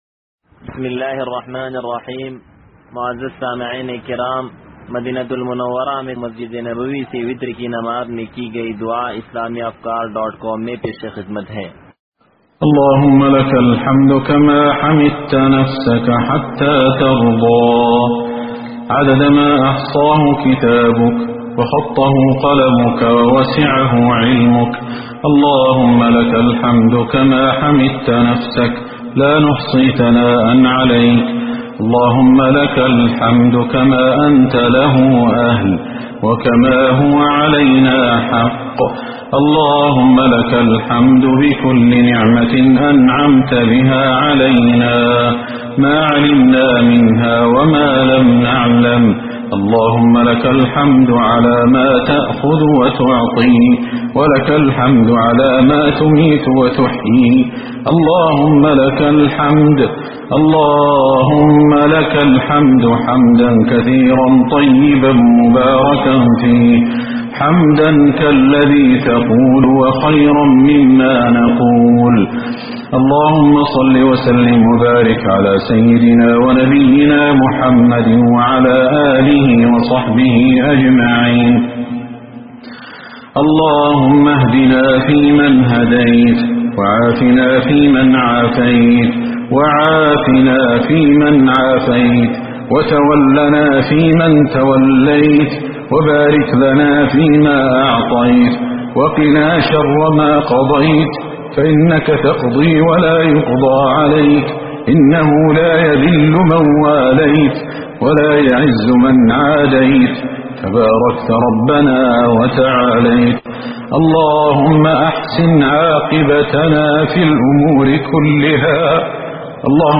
مدينة المنوّرة دعاء – 28 رمضان 1442